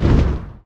firepea.ogg